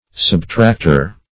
Search Result for " subtracter" : Wordnet 3.0 NOUN (2) 1. a person who subtracts numbers ; 2. a machine that subtracts numbers ; The Collaborative International Dictionary of English v.0.48: Subtracter \Sub*tract"er\, n. 1.